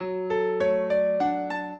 piano
minuet13-8.wav